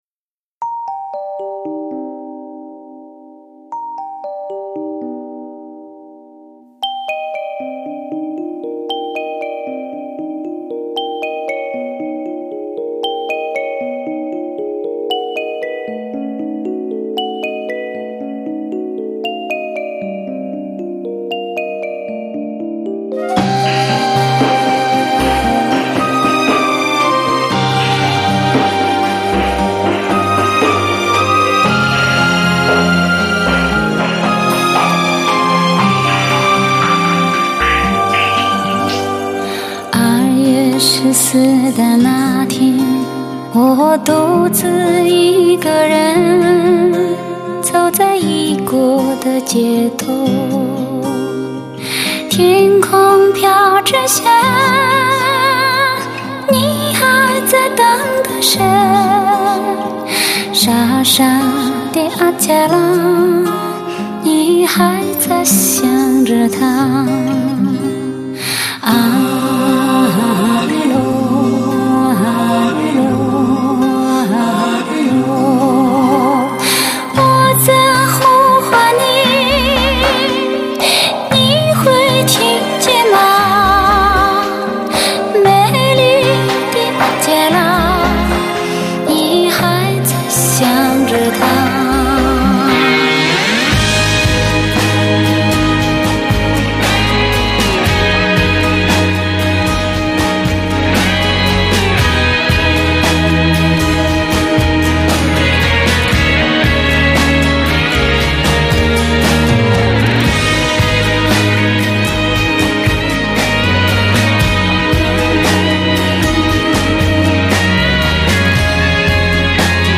德国版黑胶LP
高音上最美丽的女高音
她的声音像山间的风一样自然，像高原的天一样明亮，像冰峰上的雪莲一样纯净。